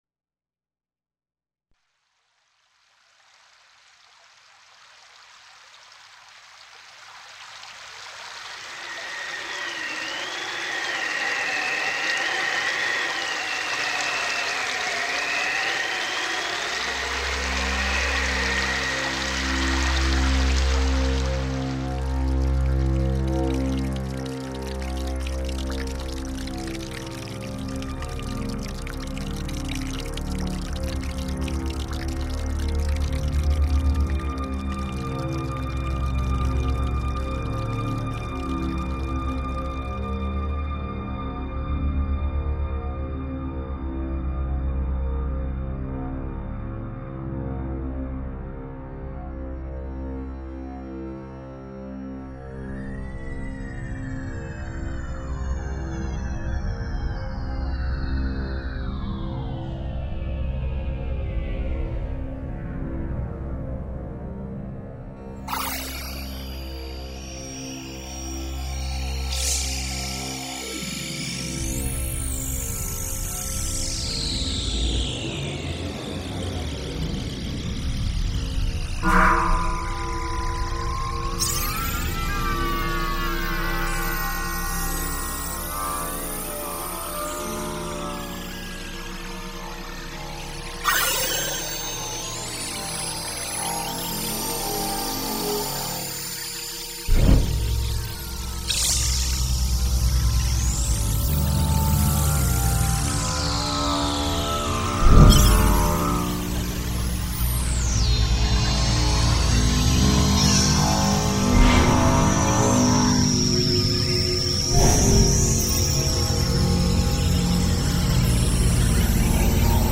Música Eletroacústica